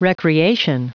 Prononciation du mot recreation en anglais (fichier audio)
Prononciation du mot : recreation